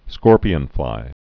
(skôrpē-ən-flī)